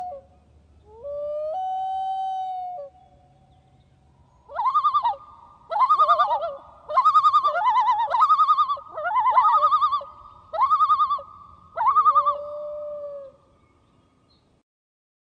黄嘴潜鸟奇特的叫声 声高而粗